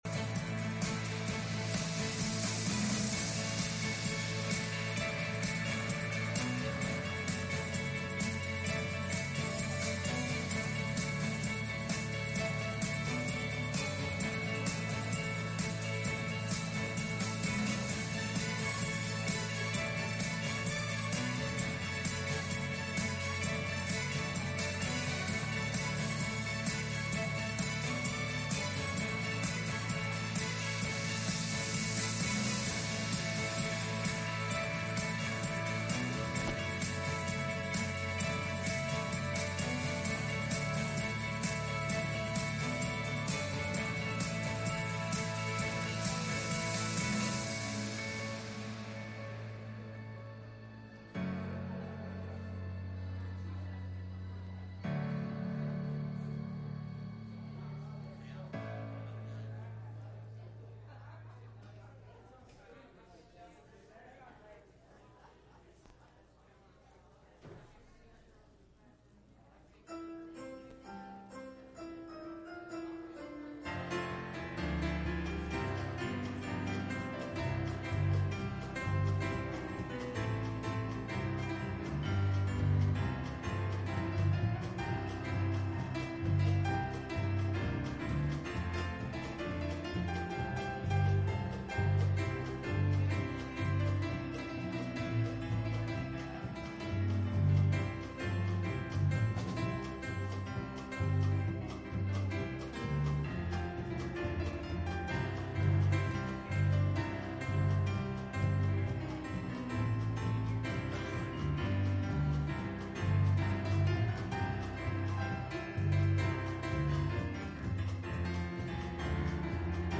Psalm 119:66 Service Type: Sunday Morning « We Need Each Other